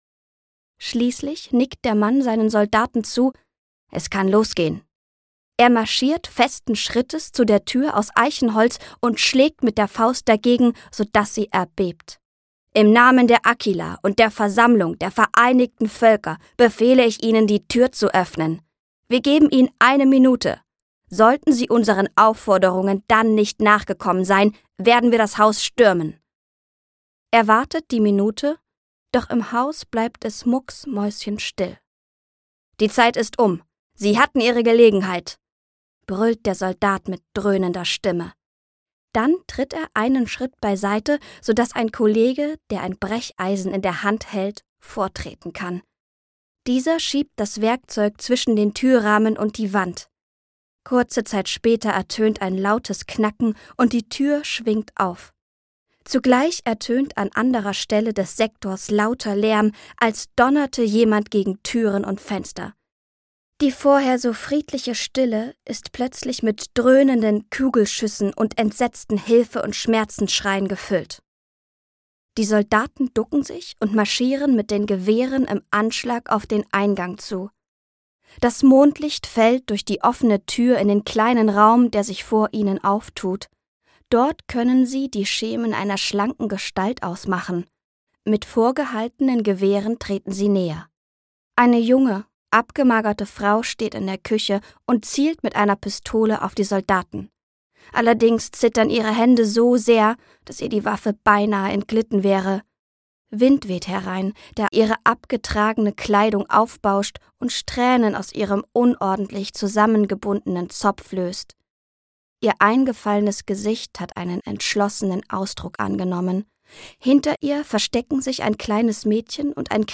Prinzessin der Elfen 3: Zerstörerische Sehnsucht - Nicole Alfa - Hörbuch